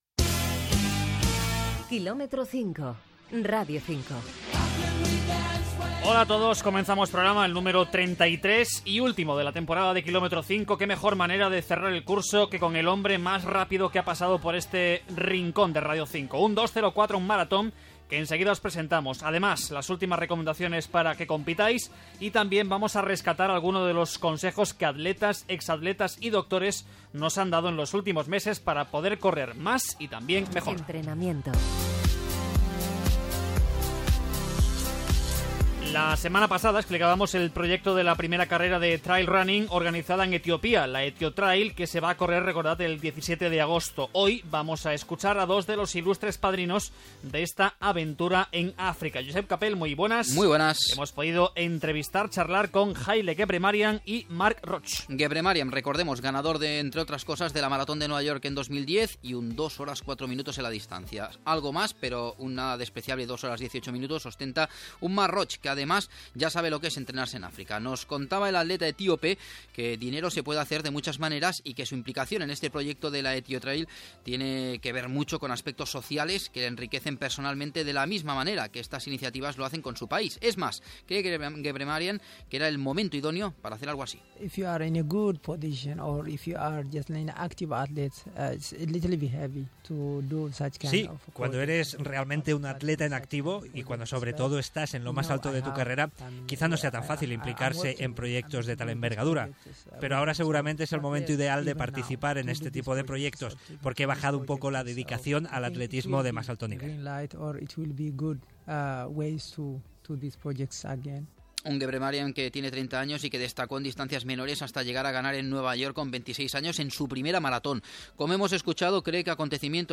Esportiu